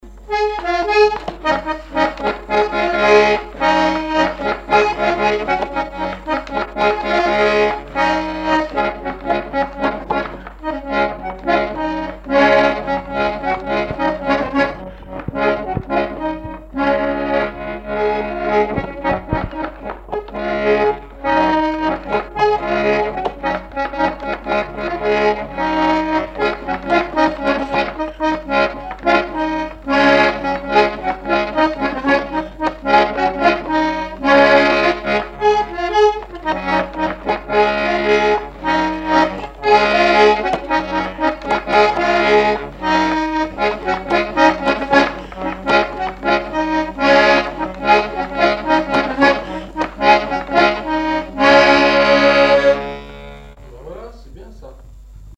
Divertissements d'adultes - Couplets à danser
branle : courante, maraîchine
Répertoire instrumental à l'accordéon diatonique
Pièce musicale inédite